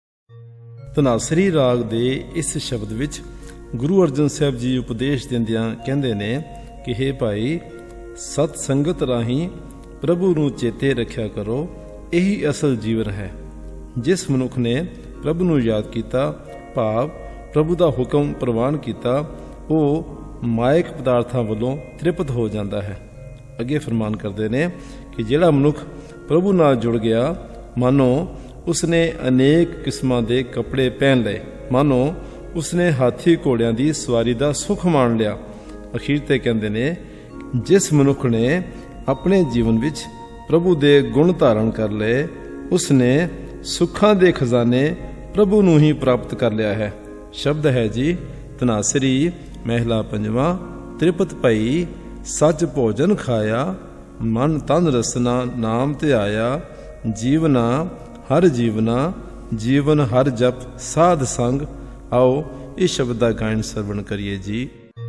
Sung in Raag Dhanasari; Taal: Roopak, Teen Taal.
This entry was posted in Shabad Kirtan and tagged , , , , .